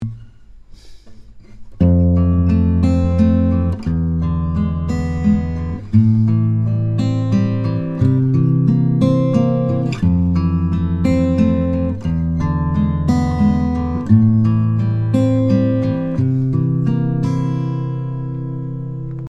The main point is to hear what these 7th chords sound like together in a sequence.
7th chord progression example 3
Progression 3 chords are F Major 7, E 7, A minor 7, and C 7.